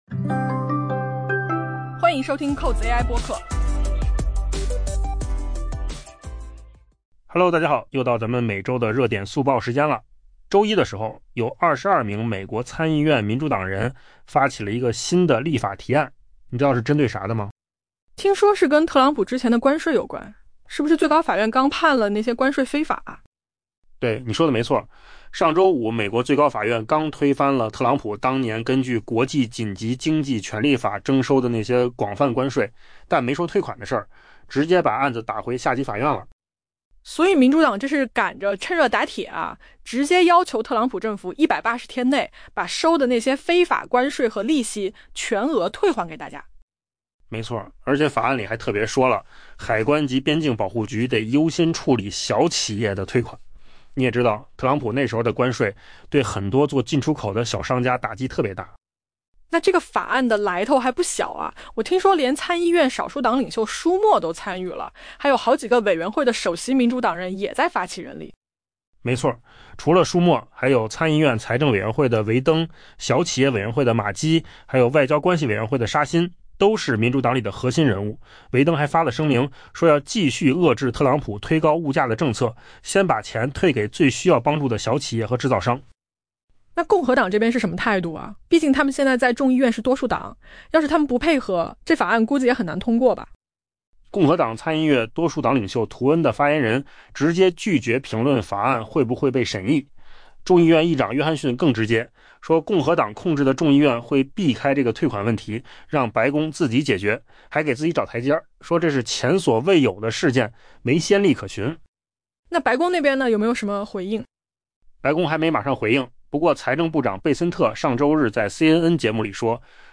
【文章来源：金十数据】AI播客：换个方
AI 播客：换个方式听新闻 下载 mp3 音频由扣子空间生成 周一，由 22 名美国参议院民主党人组成的团体发起一项立法， 要求特朗普政府在 180 天内，全额退还被美国最高法院裁定为非法的关税收入及利息。